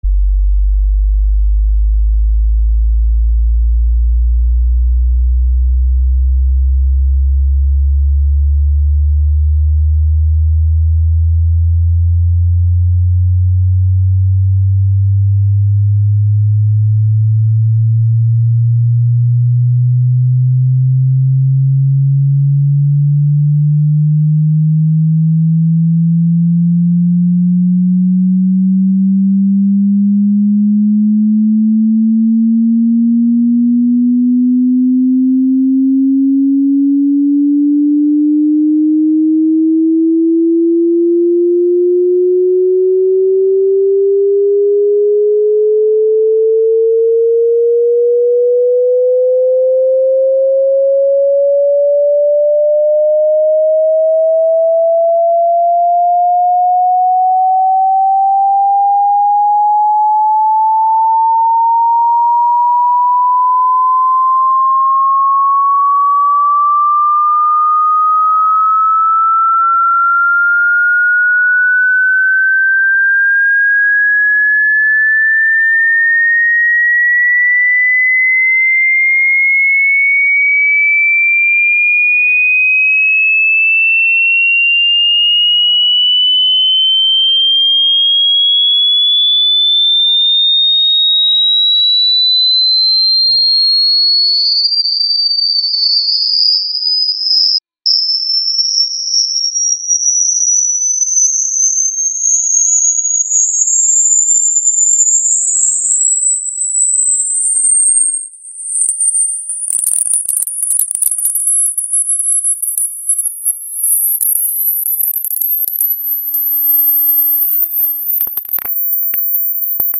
Звуки для прочистки динамиков
Разные частоты и тональности помогают вытеснить воду, устранить хрипы и восстановить качество звука.
Звук для удаления воды из динамика телефона